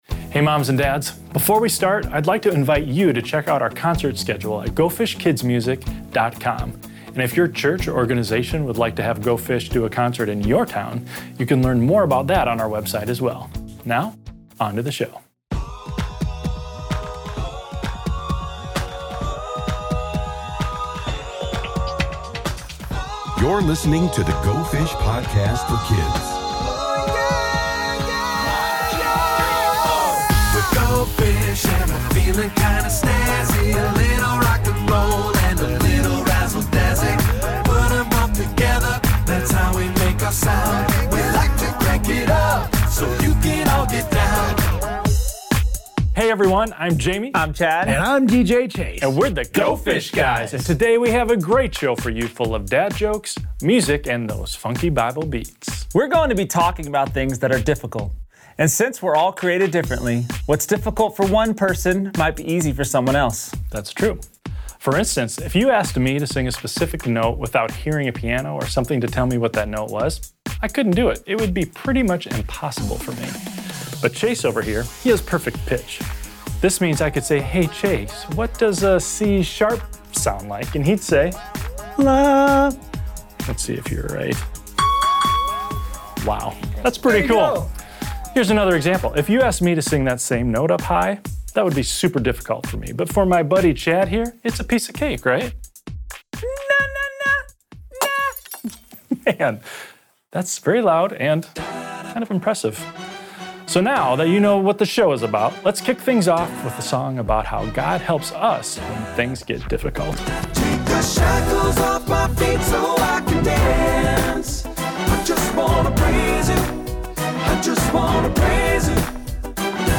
This one’s full of laughs, music, and even some tips to help with those hard-to-do things we all face. The Go Fish Guys kick things off by showing us how everyone has different strengths and struggles.